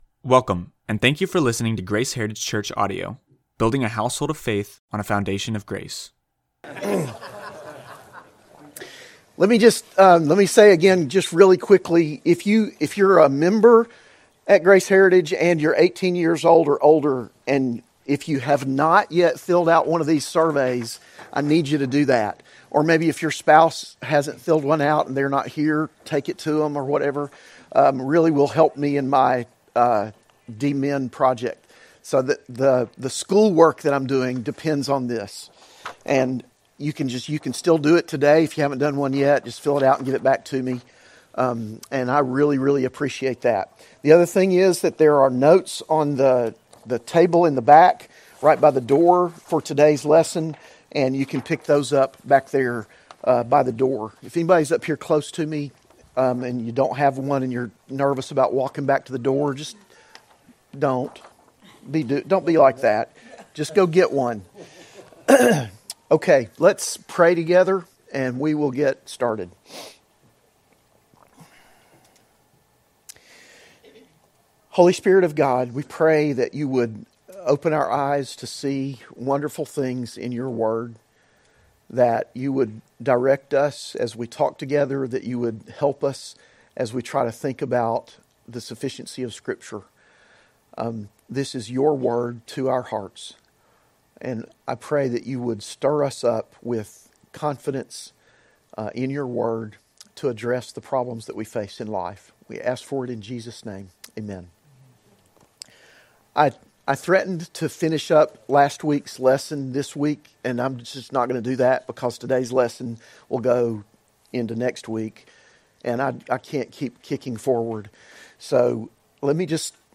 This Bible Study includes a PowerPoint, so both video and audio are available.